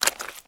STEPS Swamp, Walk 10.wav